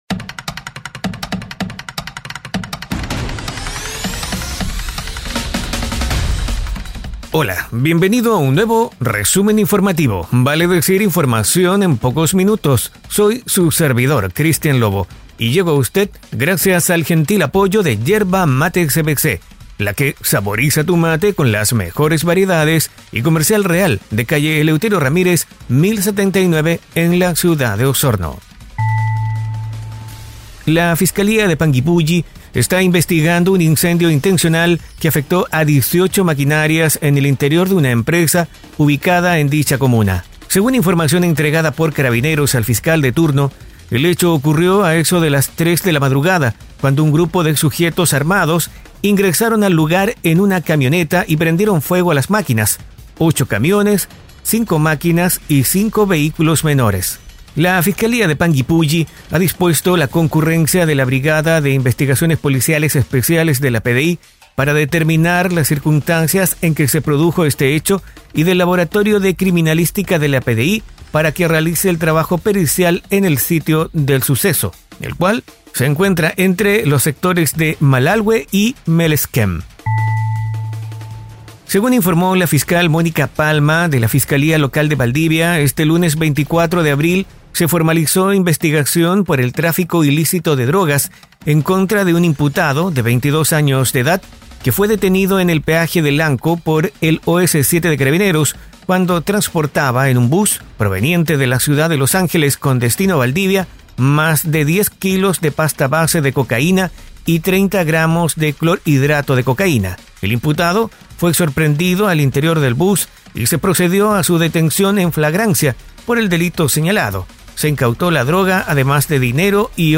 🐺Resumen informativo es un audio podcast con una decena de informaciones en pocos minutos, enfocadas en la Región de Los Lagos